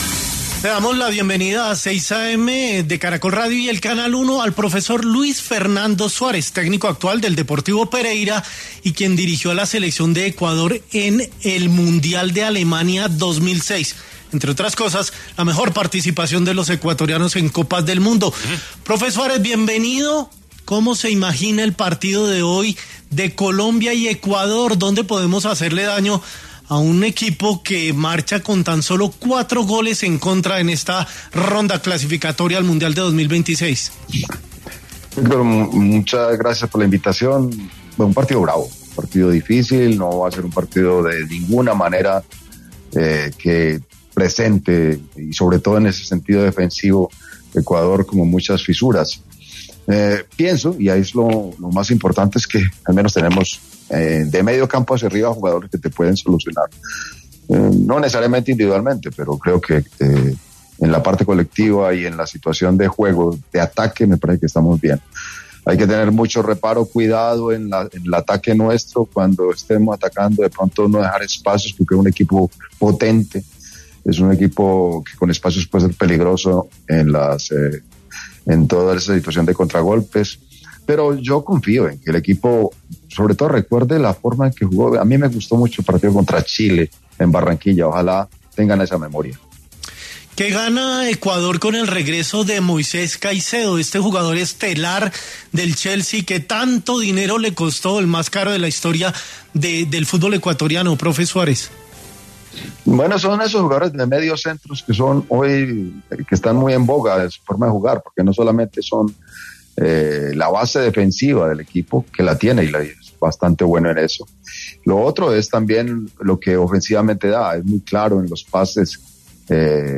Luis Fernando Suárez habló en 6AM de Caracol Radio sobre el venidero duelo entre Colombia y Ecuador por las Eliminatorias sudamericanas.